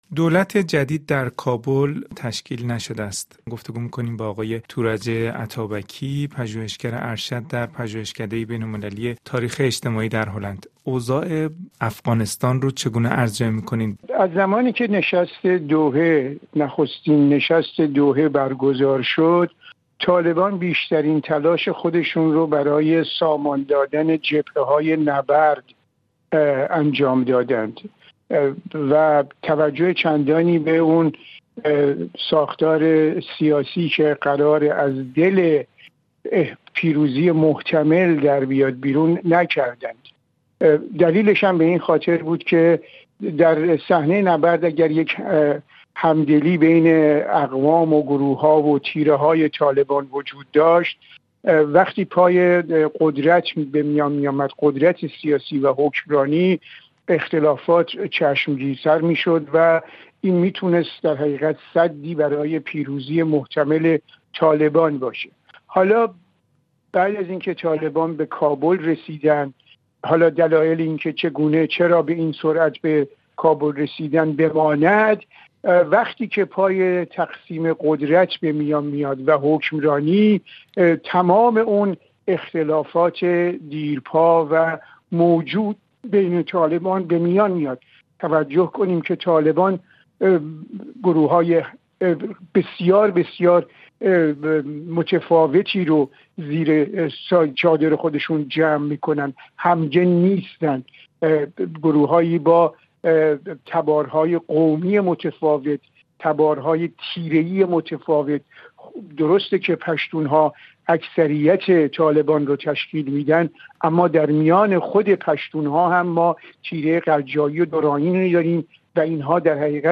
در گفت‌وگو با رادیو بین‌المللی فرانسه